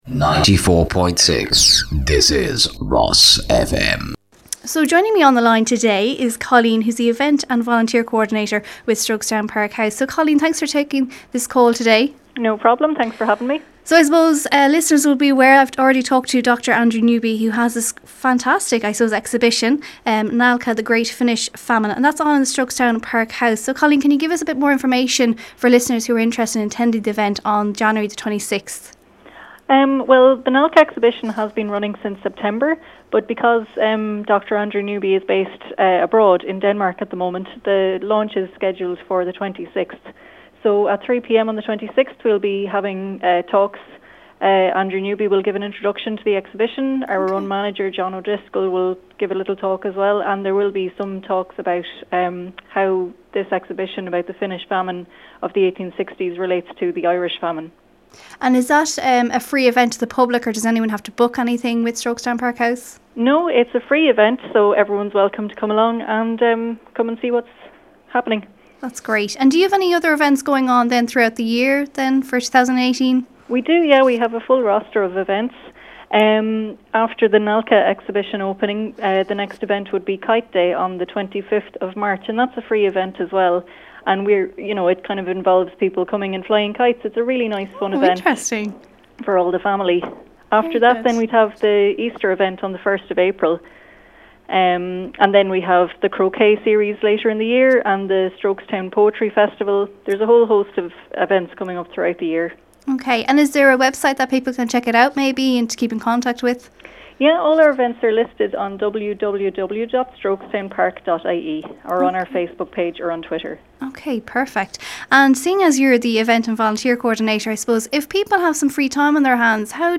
Interview - RosFM 94.6